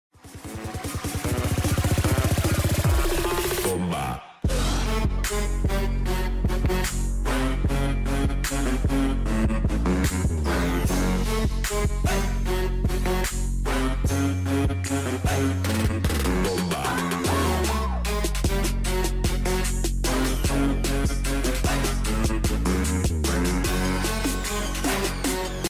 M24 Shoot Slow Motion Video